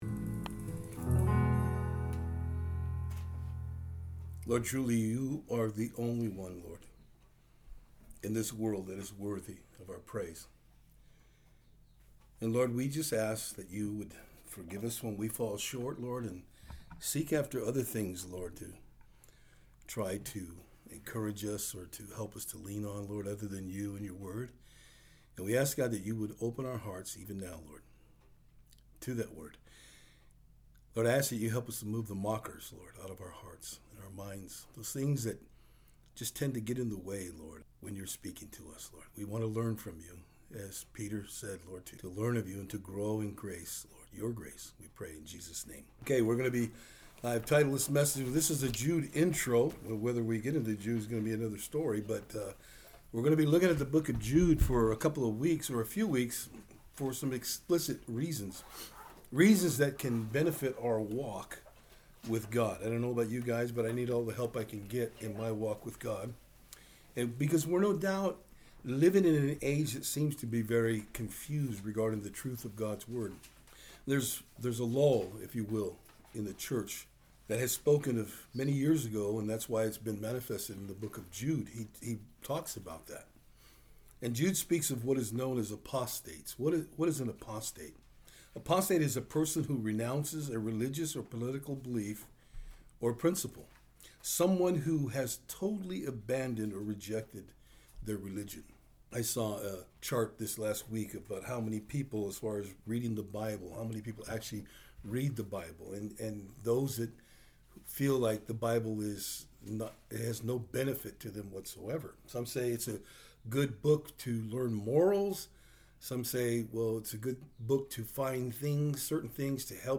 Service Type: Thursday Afternoon